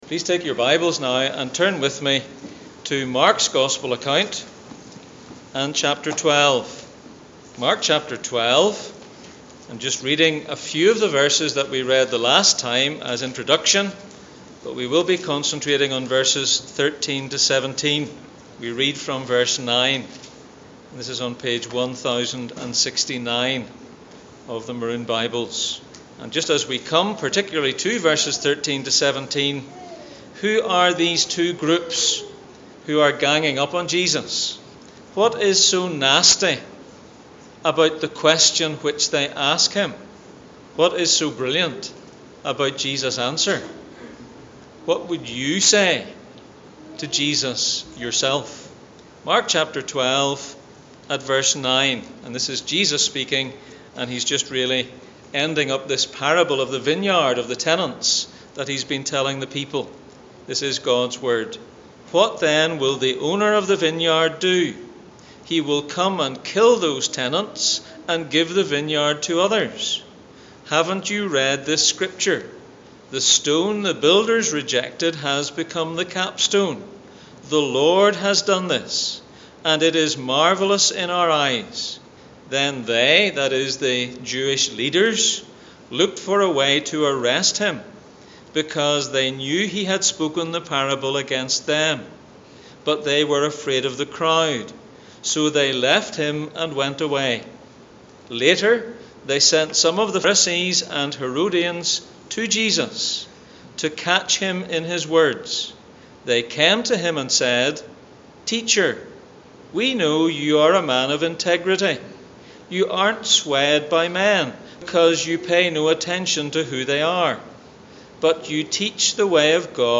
Mark 12:9-17 Service Type: Sunday Morning %todo_render% « Beatitudes in the Psalms What is a true branch?